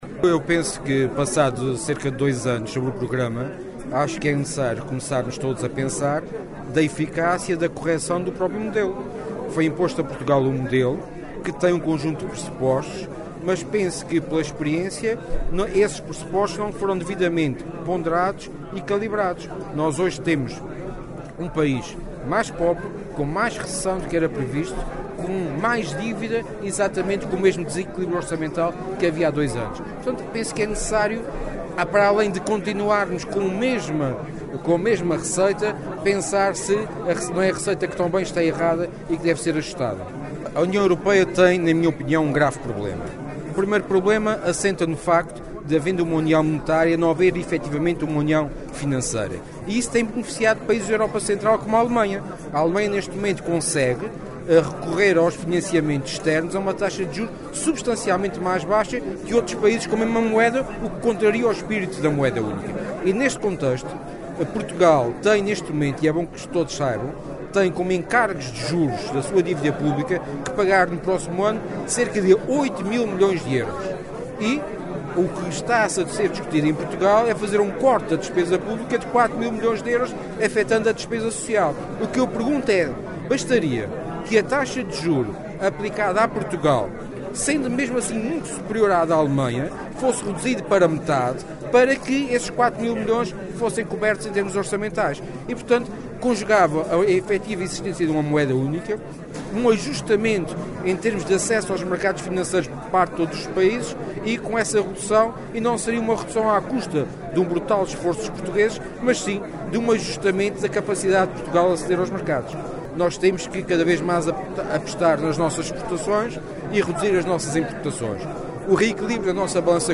Sérgio Ávila, que falava na sessão de entrega de prémios da revista "As 100 Maiores Empresas dos Açores”, a que assistiram cerca de uma centena e meia de empresários, acrescentou que os pressupostos da assistência financeira ao país não foram devidamente ponderados.